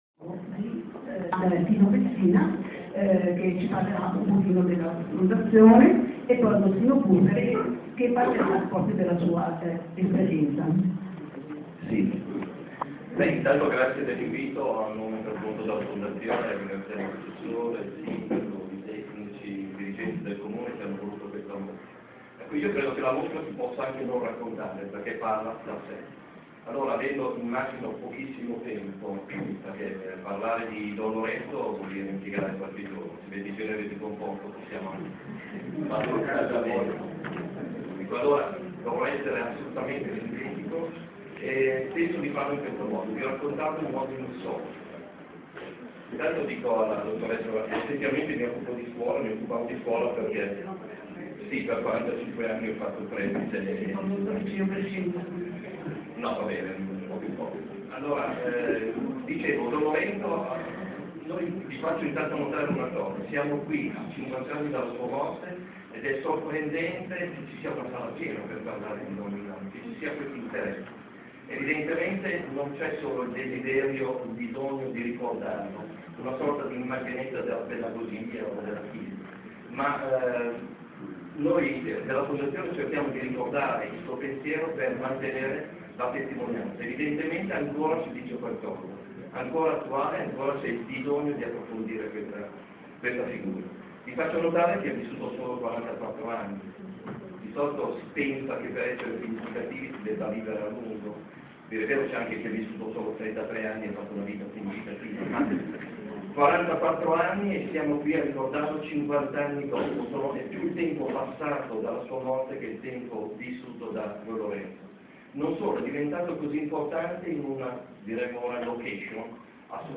Un pubblico delle grandi occasioni quello che stasera, sabato 4 febbraio, a Palazzo delle Paure ha partecipato all’inaugurazione della Mostra su Don Milani “Il silenzio diventa voce”; che si protrarrà fino al 30 aprile.